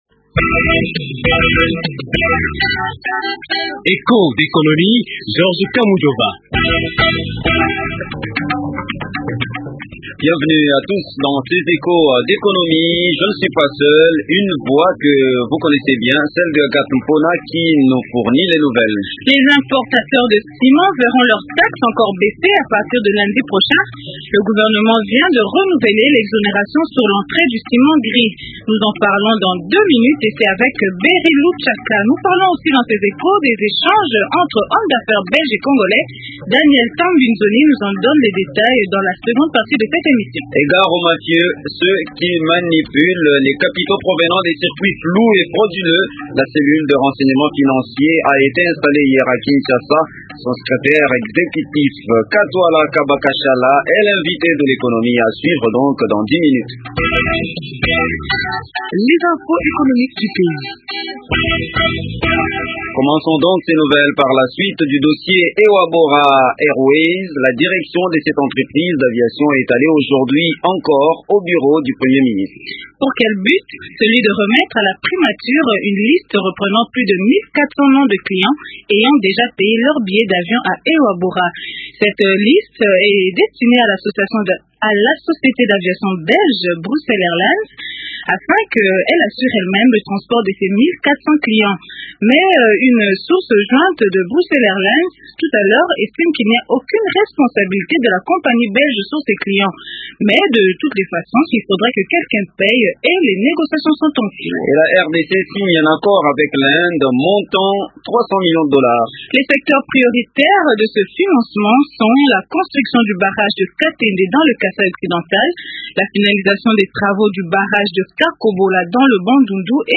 Son secrétaire exécutif, Katwala Kaba Kashala, est l’invité d’Echos d’économie.